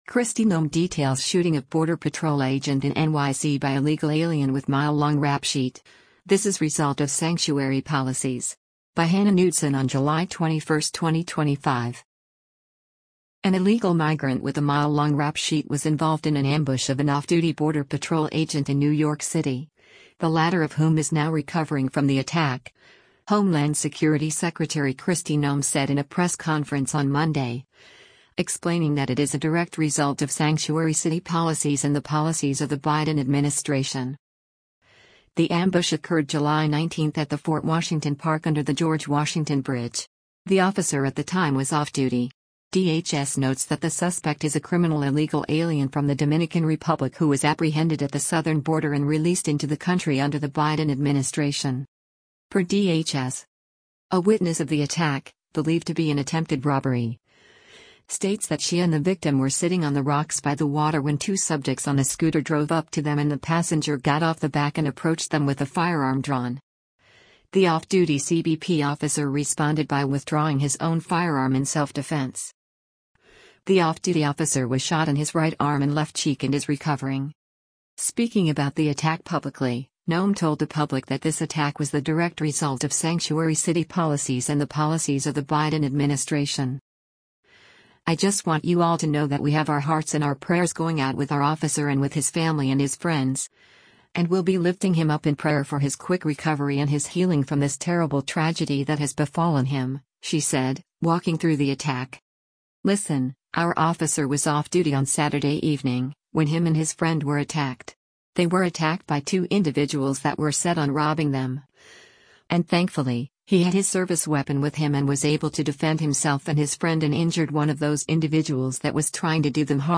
An illegal migrant with a “mile long” rap sheet was involved in an ambush of an off-duty border patrol agent in New York City, the latter of whom is now recovering from the attack, Homeland Security Secretary Kristi Noem said in a press conference on Monday, explaining that it is a direct result of sanctuary city policies and the policies of the Biden administration.